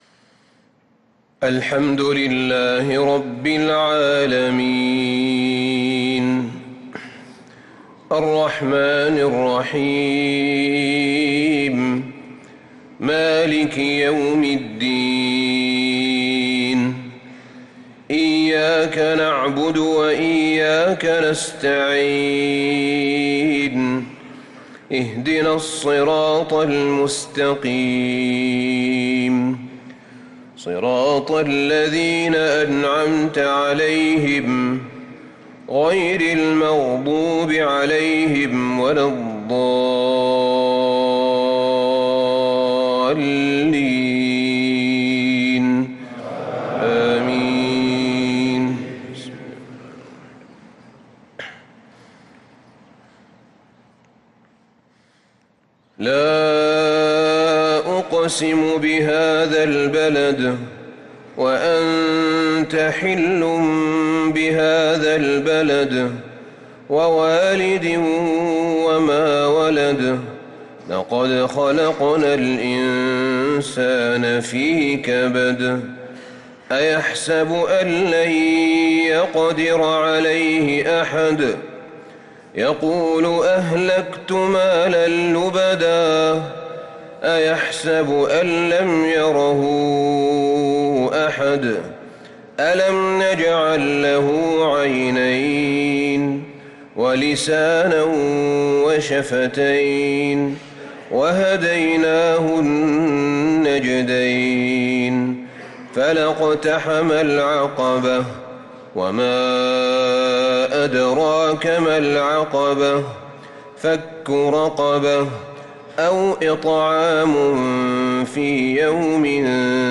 صلاة العشاء للقارئ أحمد بن طالب حميد 3 شوال 1445 هـ
تِلَاوَات الْحَرَمَيْن .